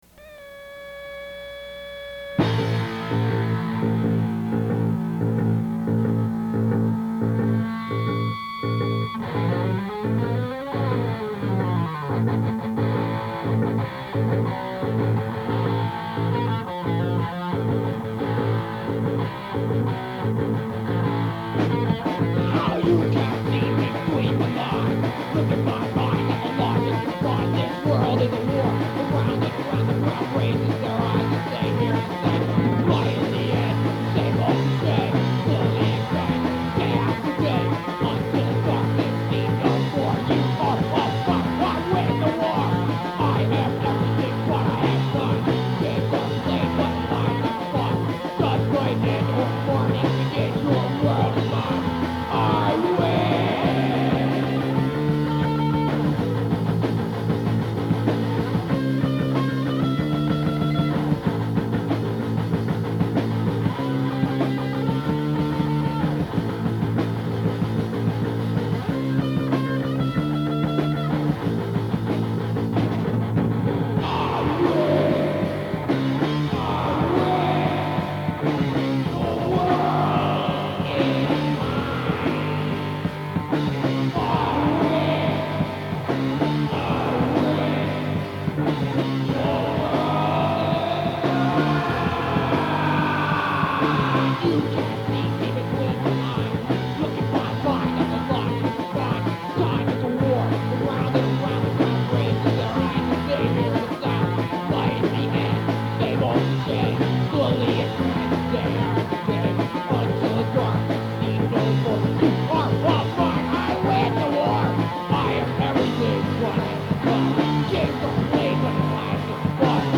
demo tape
Guitar
Drums
on bass.